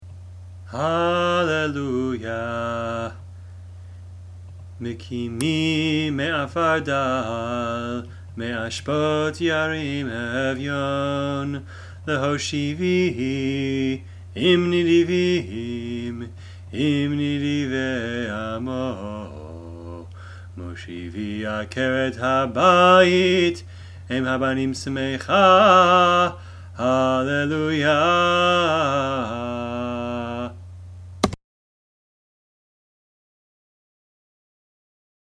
02 Hallel – Psalm 113 (chanting)
02-hallel-psalm-113-chanting.mp3